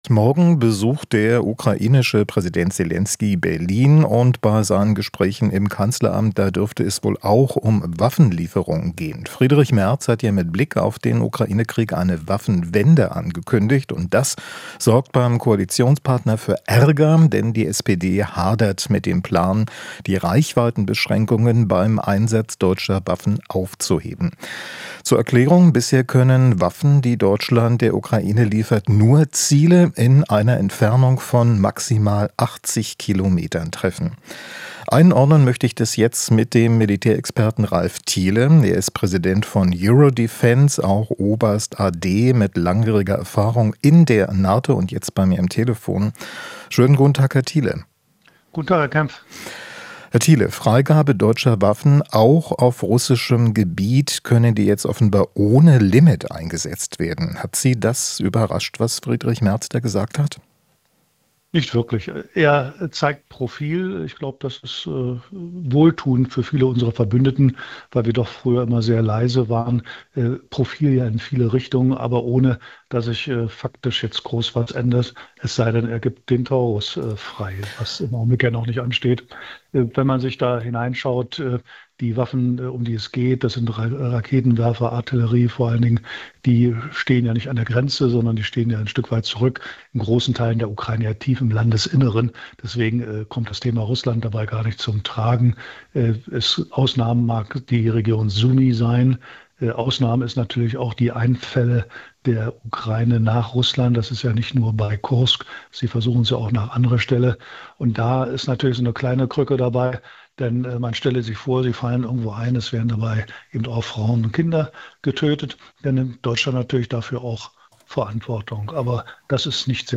Interview - Militärexperte: Merz zeigt Profil